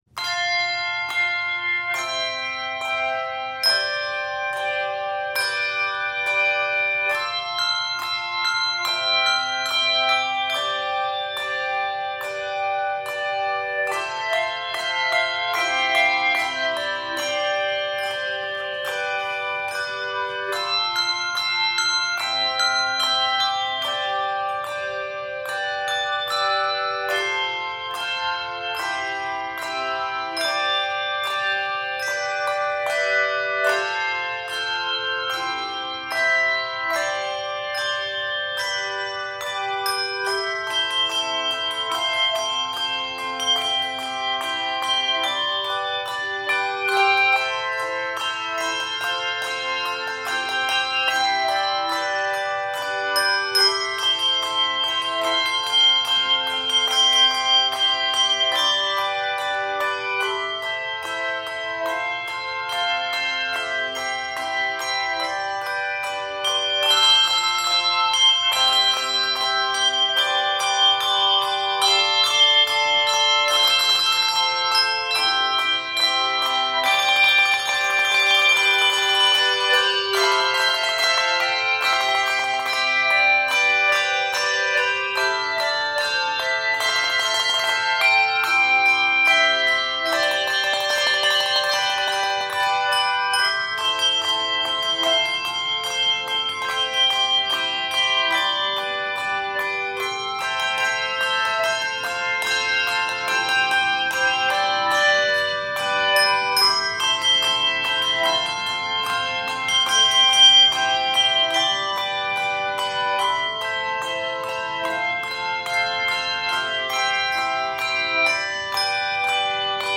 for 2-3 octaves